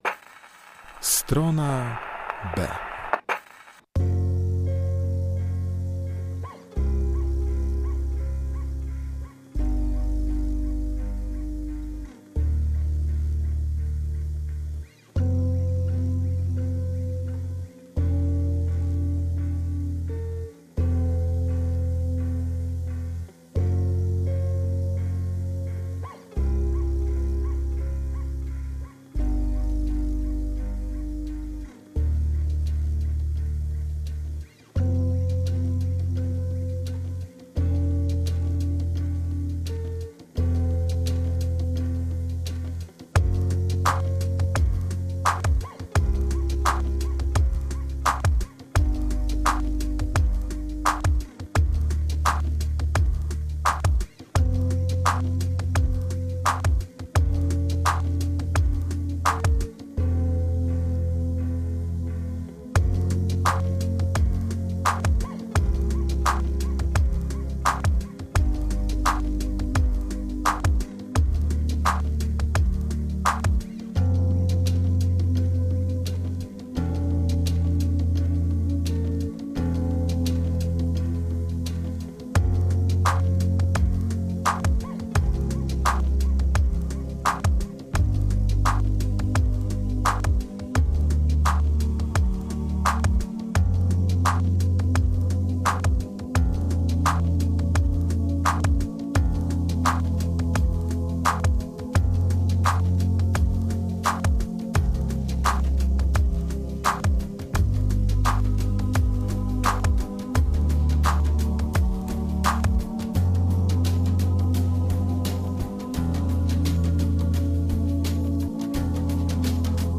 Elektronika